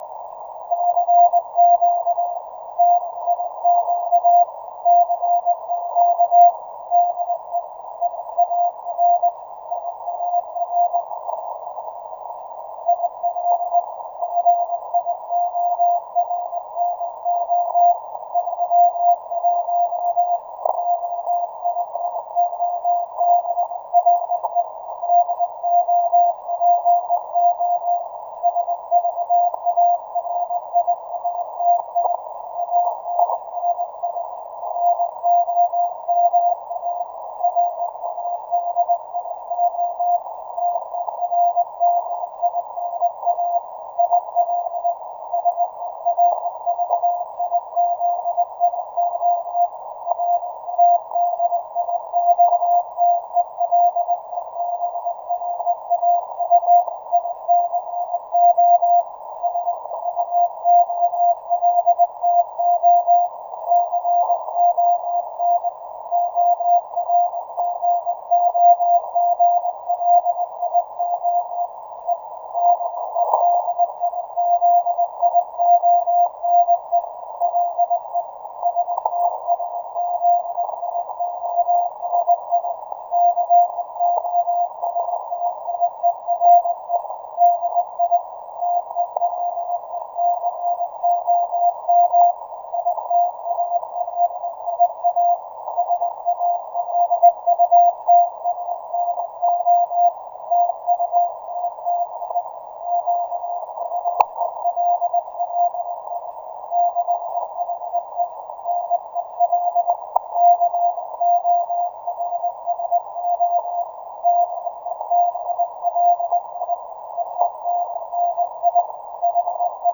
Il giorno 31 ottobre dopo svariati anni, ROMA RADIO termina il servizio RADIOTELEGRAFICO svolto con orgoglio e dedizione e le STAZIONI COSTIERE DI TELECOM ITALIA salutano tutti quelli che nel corso degli anni hanno servito questa professione con capacità e sacrificio e augurano a tutti buon lavoro.